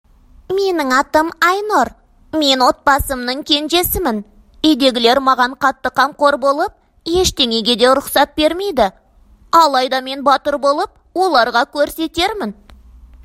• 2Kazakh Female No.1
Animation character voice【Girl】